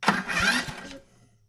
电动工具 01 " 割草机电动假启动 02
描述：割草机假启动（指不启动）。
标签： 发动机 电力 假的 汽油 工业 园林绿化 lawm 割草机 电力 失速 启动 工具
声道立体声